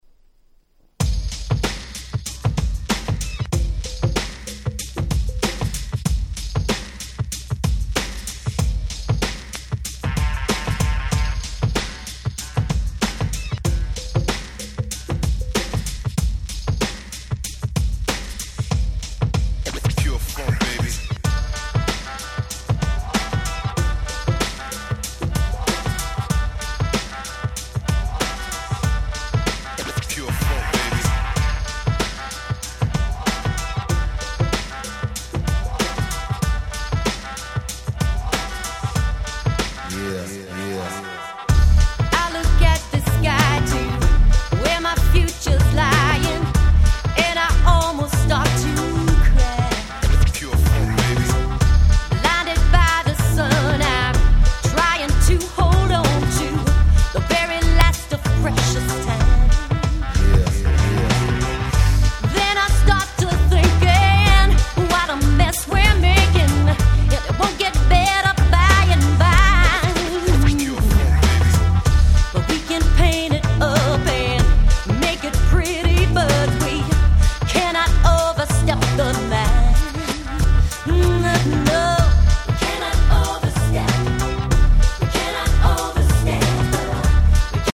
UK Promo Only Remix !!